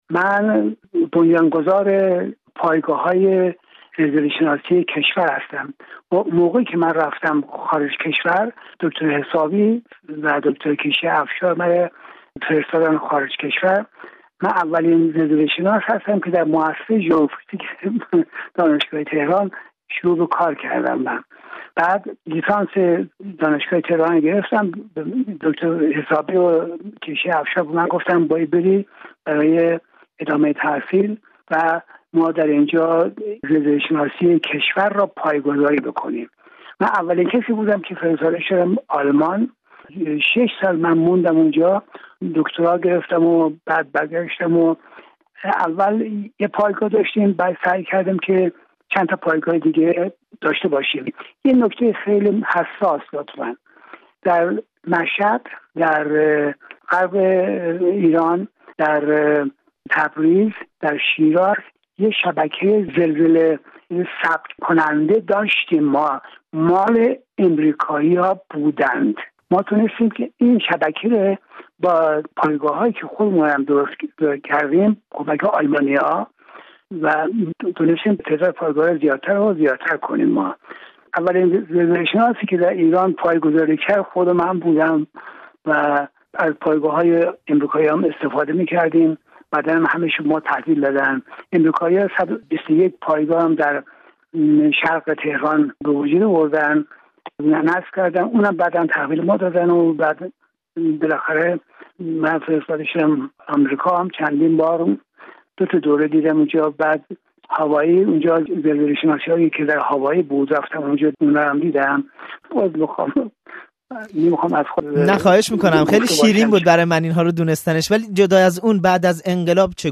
بهرام عکاشه، پدر دانش زلزله‌شناسی در ایران، در ۸۹ سالگی درگذشت. او در آخرین گفت‌وگویش با رادیو فردا می‌گوید؛ ایرانی‌ها به جای توکل به آسمان باید زلزله را پدیده‌ای زمینی تلقی کنند.